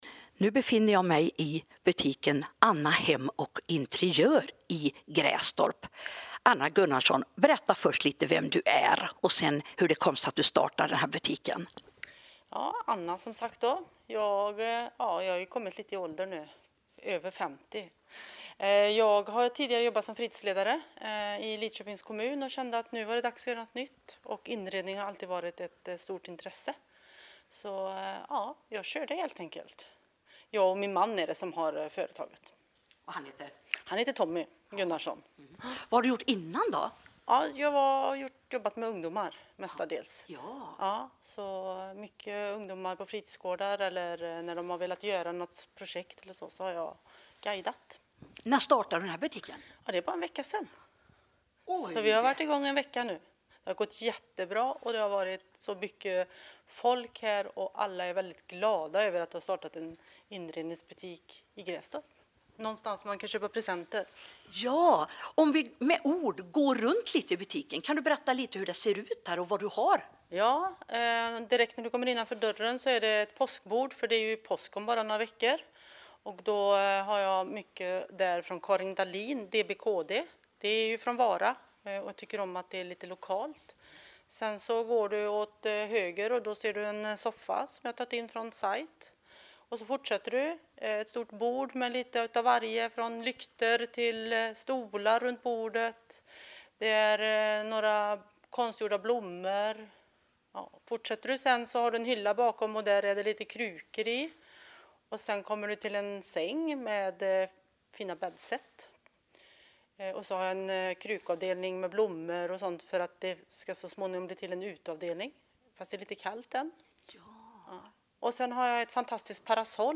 Trevlig intervju och fina bilder.